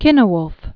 (kĭnə-wlf) or Cyn·wulf (kĭnwlf) fl. c. 800?